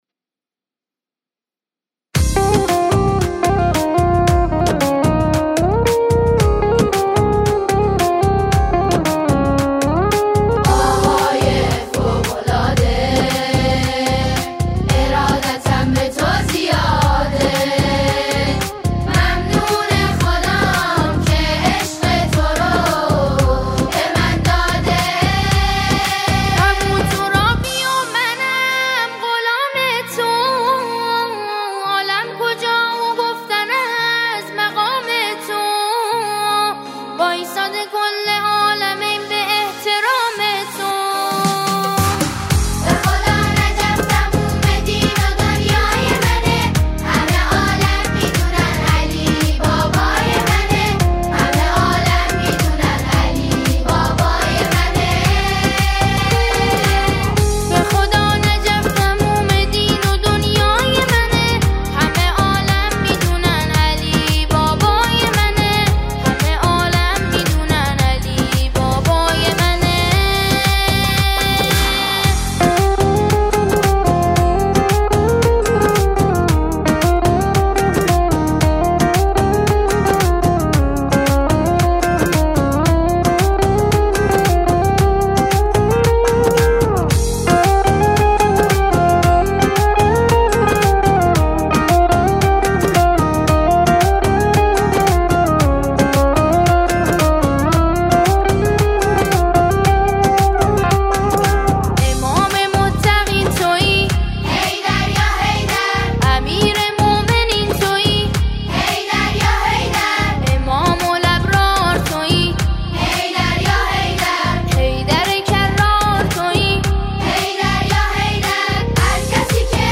اثری معنوی، پرانرژی و فرهنگی برای علاقه‌مندان به هنر آیینی.